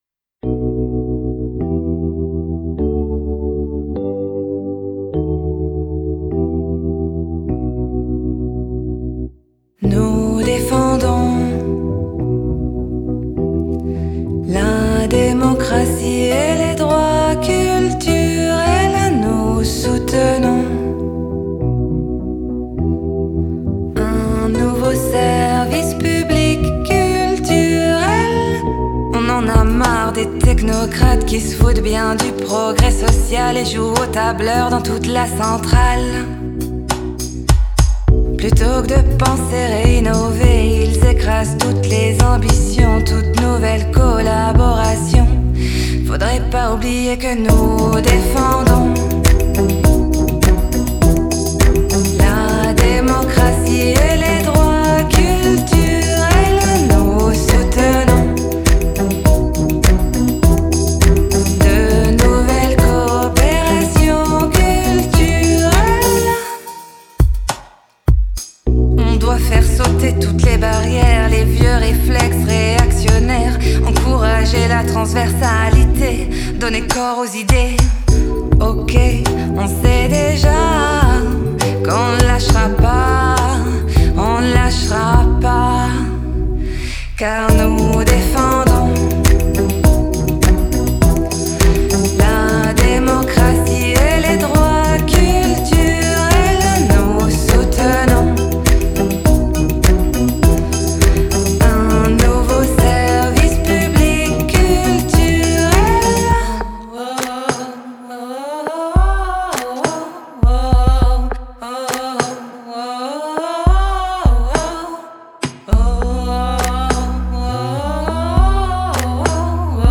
Culture déconfinée (chanson)